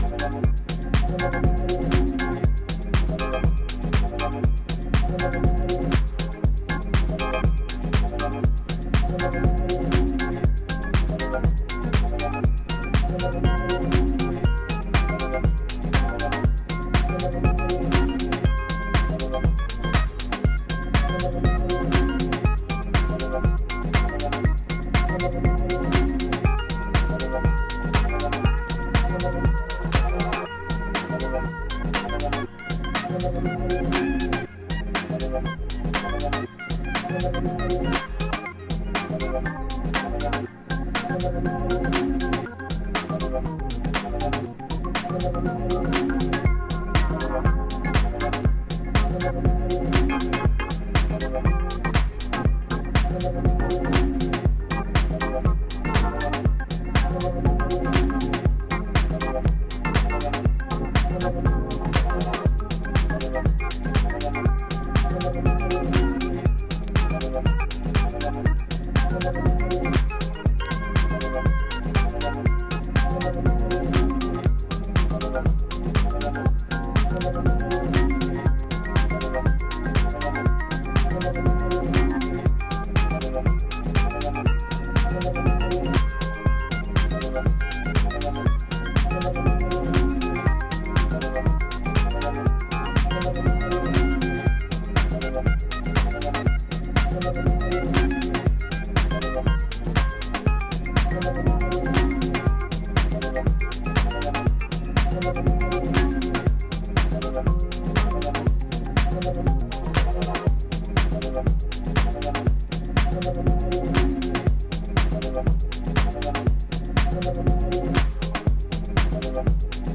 頭をもたげるようなレイトナイト感覚が充満した、彼らしい個性が光る1枚です！